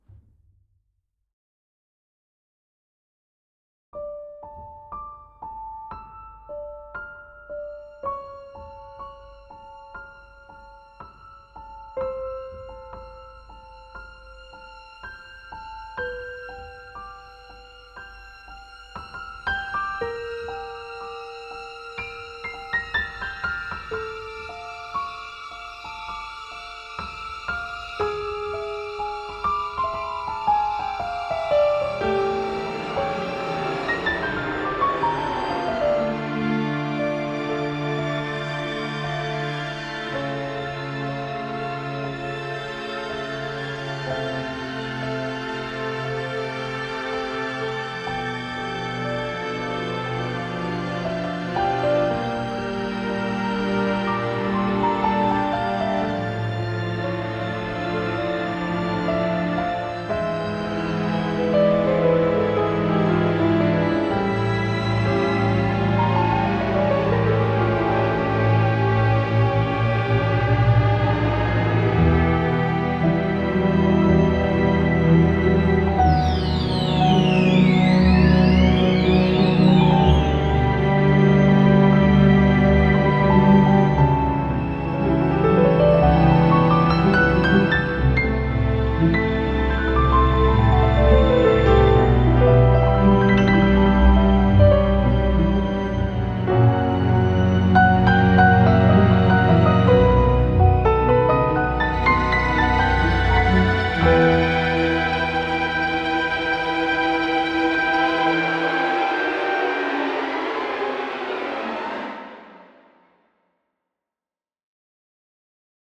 Если в какой-то (скорее редкой) ситуации подойдёт, то звучит очень хорошо.